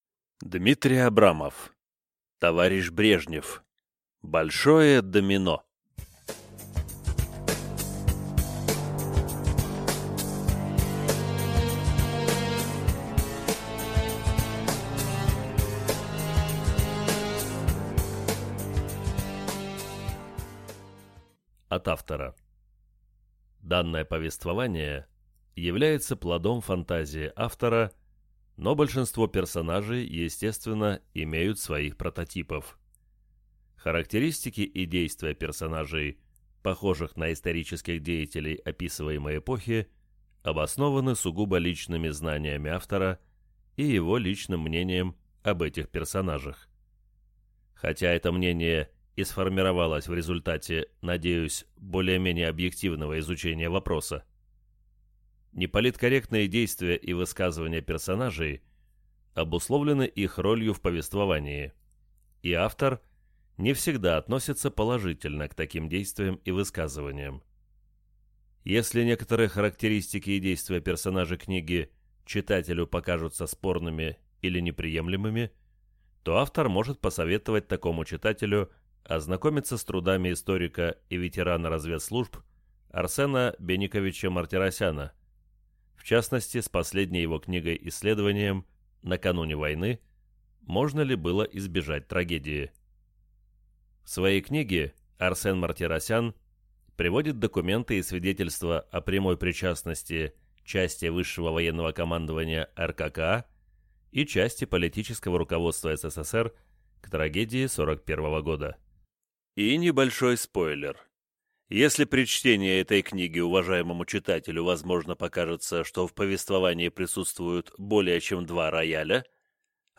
Аудиокнига Товарищ Брежнев. Большое Домино | Библиотека аудиокниг